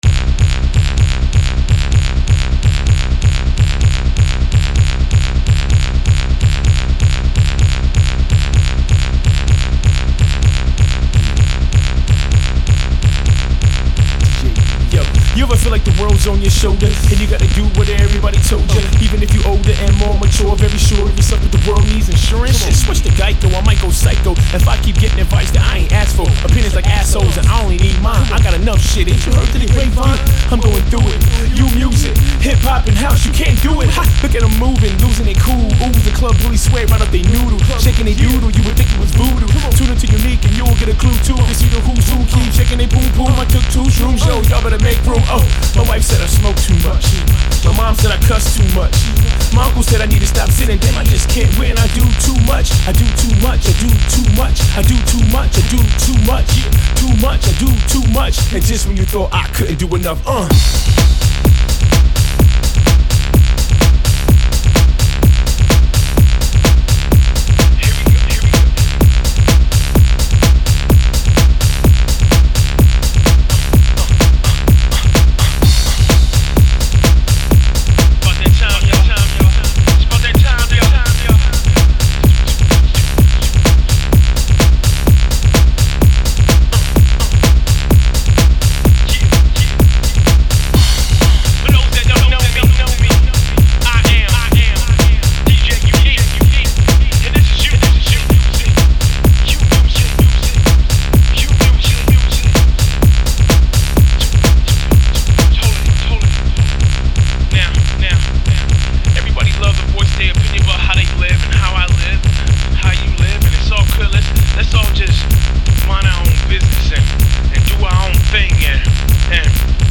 Genre: Hip House.